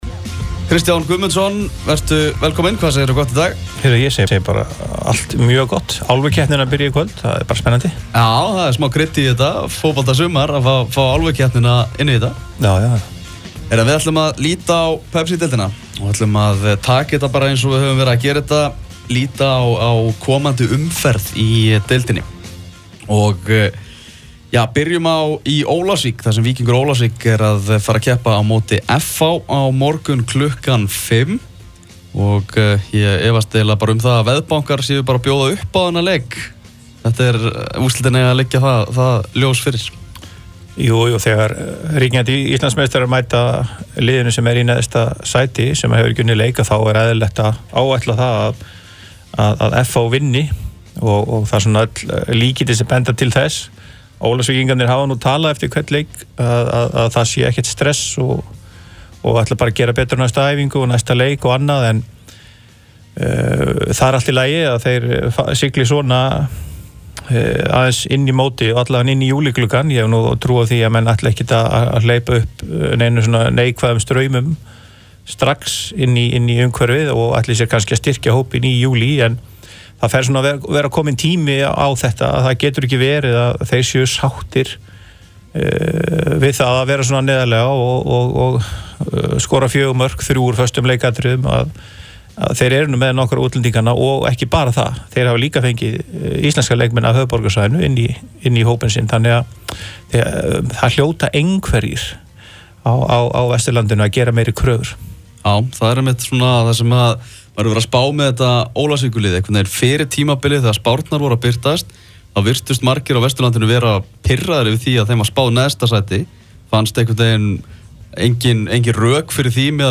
Viðtalið